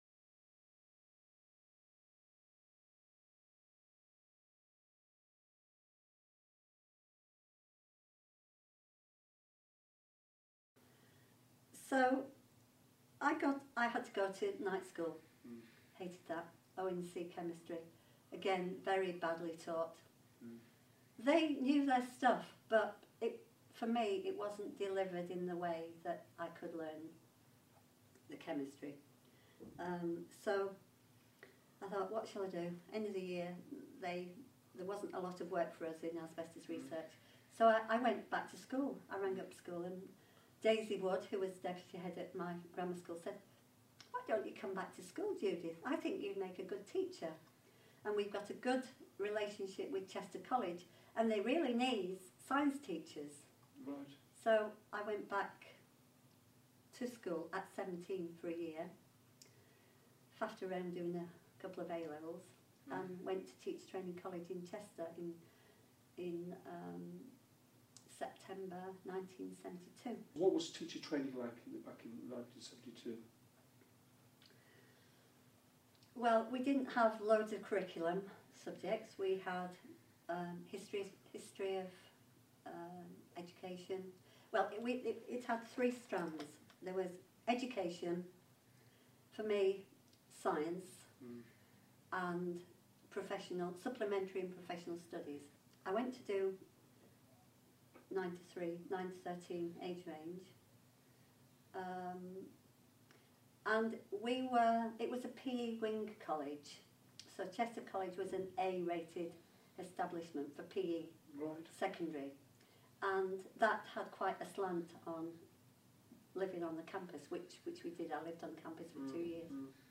Part of a series of interviews with veteran teachers and teacher educators.